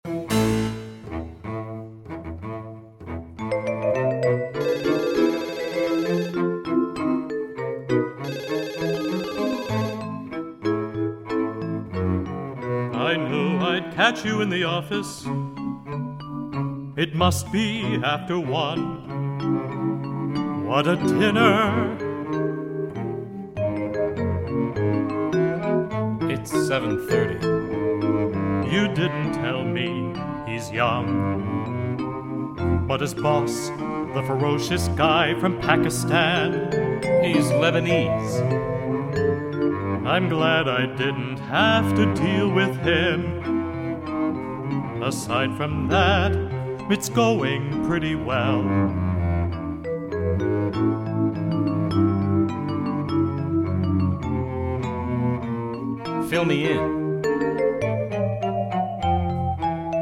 cello
baritone
soprano
synthesizer